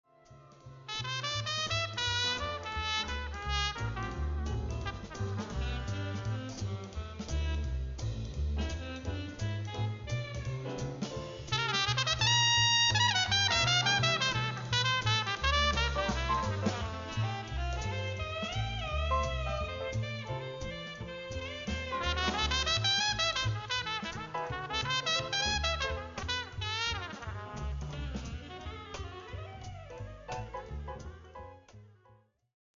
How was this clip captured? Recorded Red Gables Studios, January 21st 2008